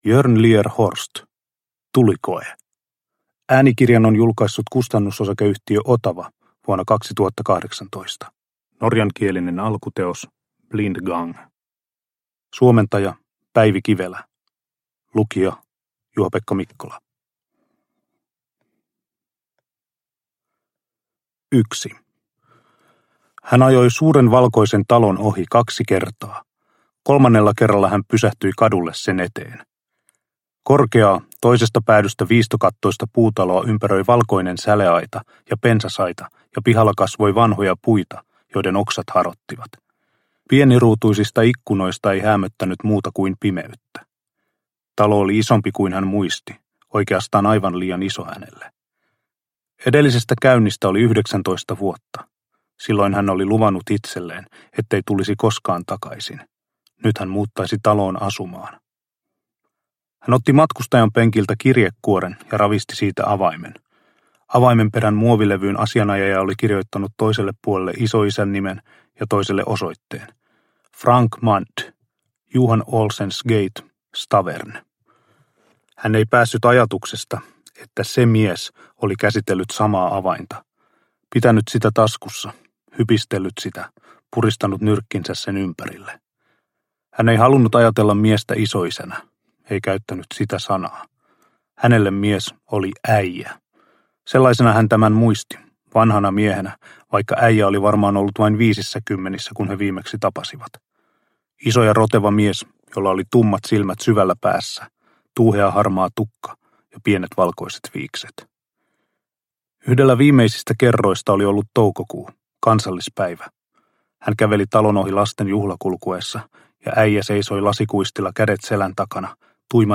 Tulikoe – Ljudbok – Laddas ner